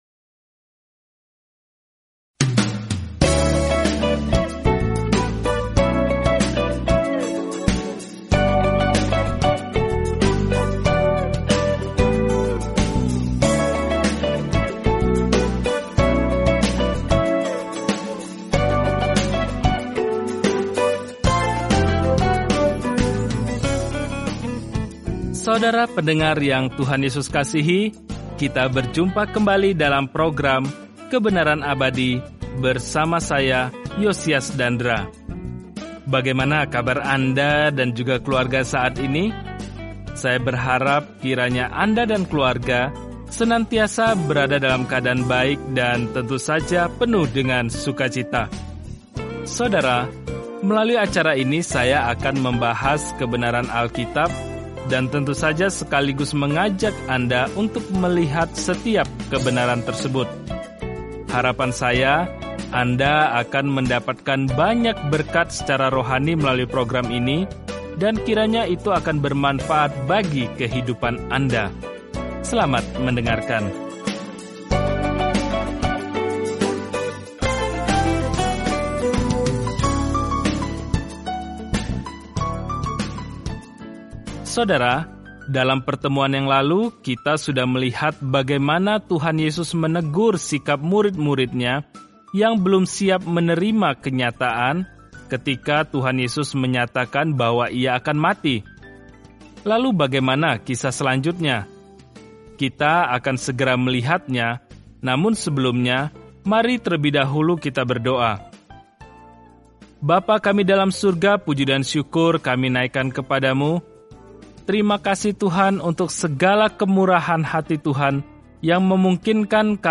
Jelajahi Markus setiap hari sambil mendengarkan studi audio dan membaca ayat-ayat tertentu dari firman Tuhan.